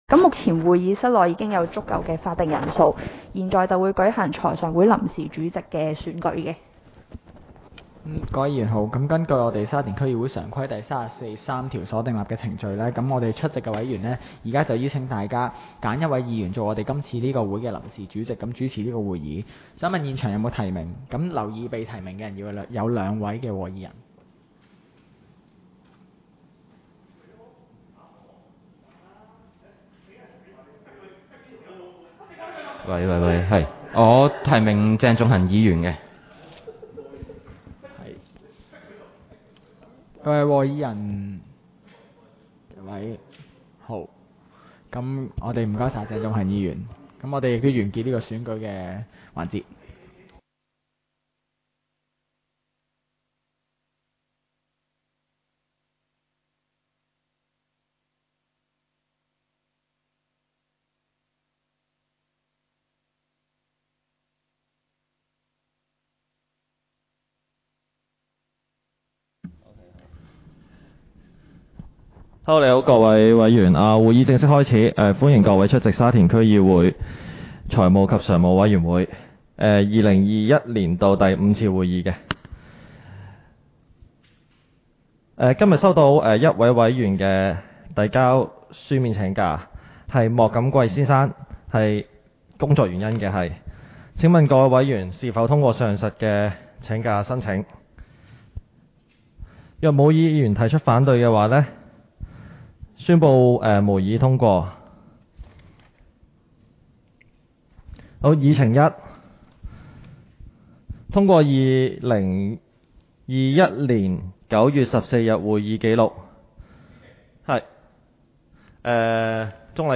委员会会议的录音记录
地点: 沙田民政事务处 441 会议室